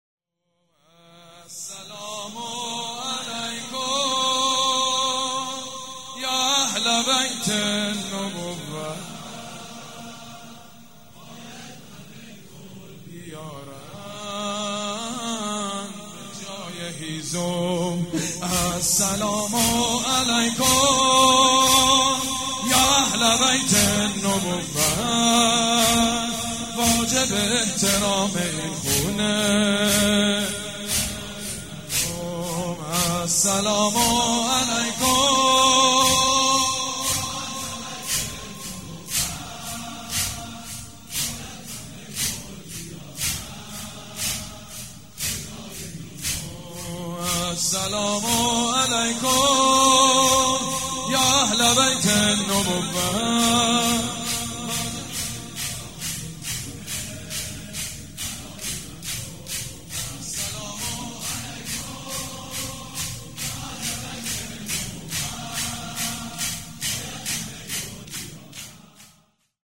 شب سوم فاطميه دوم١٣٩٤
شور
مداح
حاج سید مجید بنی فاطمه
مراسم عزاداری شب سوم